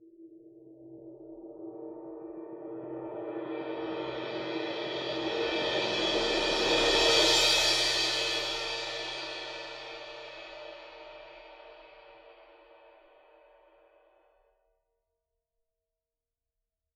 susCymb1-cresc-Long_v1.wav